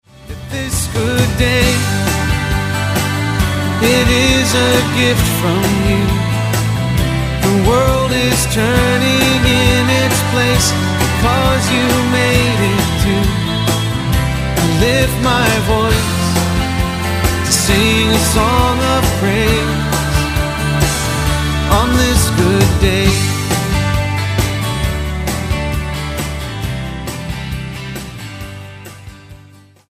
STYLE: Roots/Acoustic
The upbeat opening track